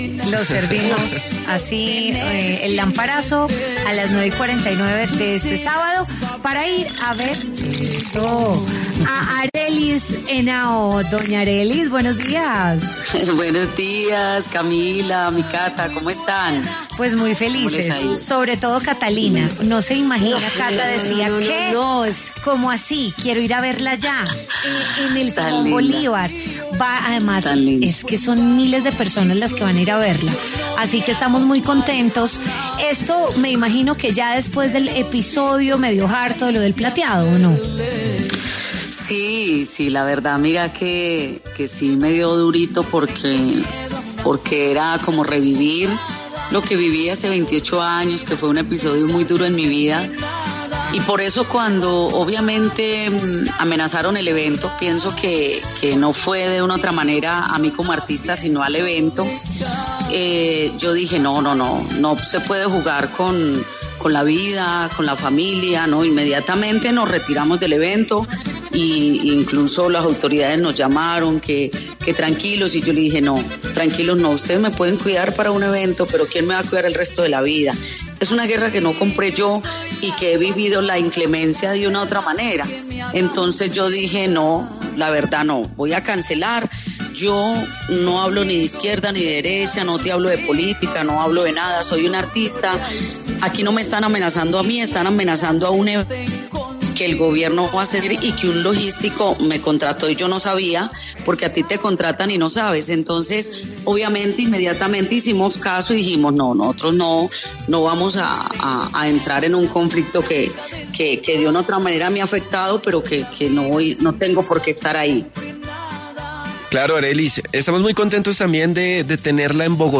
La cantante Arelys Henao habló en W Fin de Semana a propósito de su presentación en Bogotá en el marco del festival Popular al Parque.